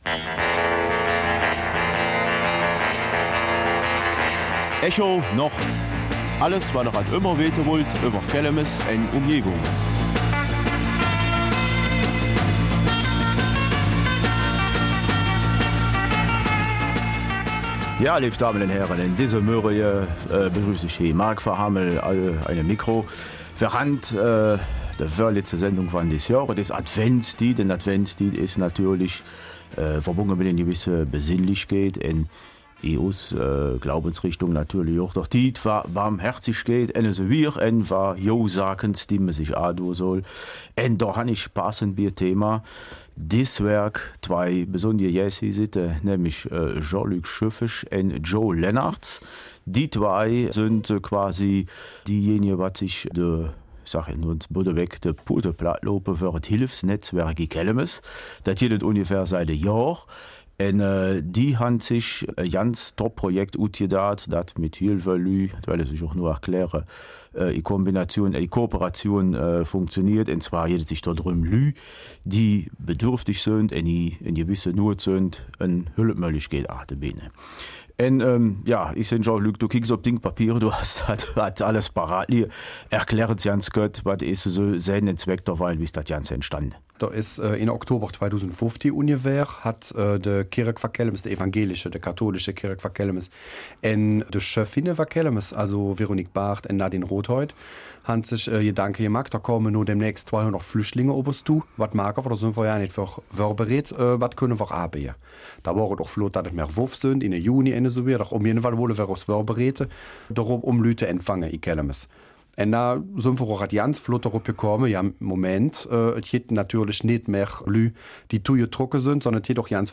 Kelmiser Mundart: Das Hilfsnetz Kelmis
Die beiden Gäste berichten über die Art der Hilfen, die Organisation und über die bisher noch nicht dagewesene Erfolgsgeschichte dieser Initiative.